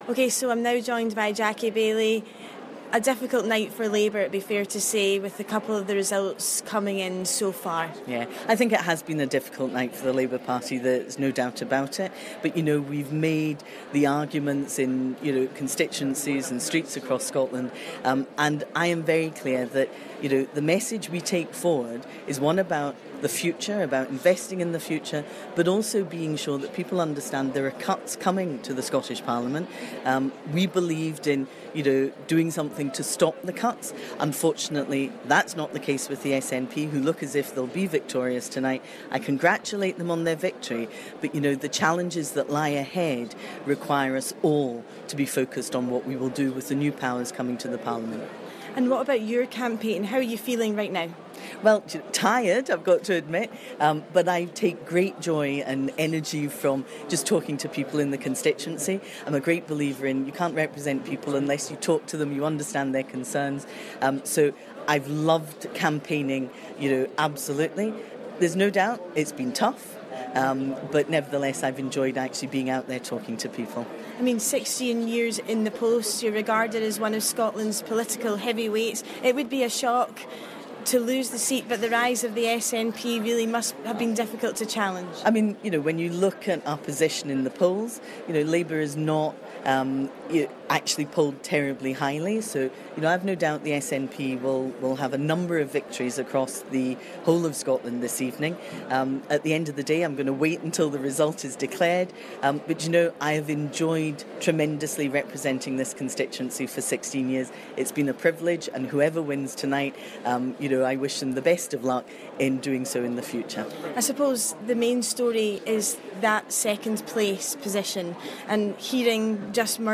Labour's Jackie Baillie is telling Clyde News it's been a difficult night for her party.